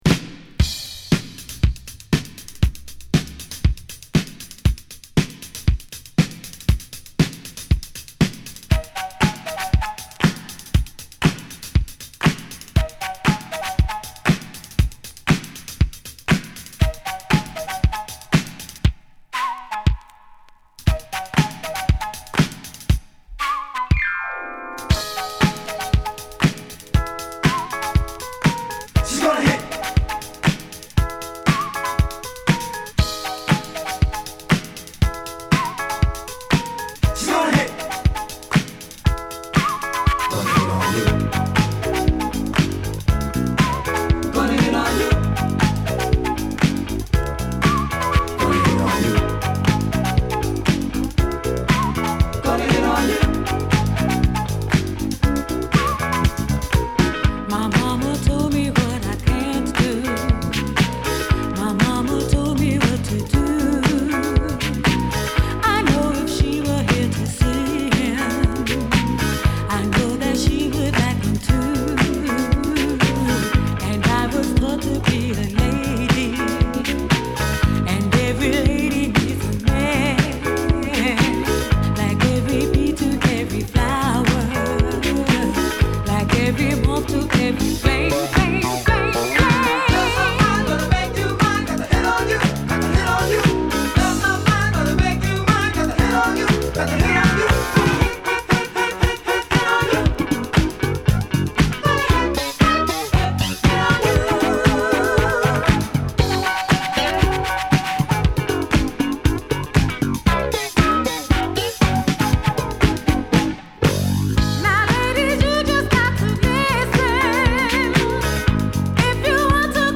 フルートやエレピのメロウなサウンドからぶっといベース、女性ヴォーカルが覚え易いサビと共に絡むナイスディスコチューン！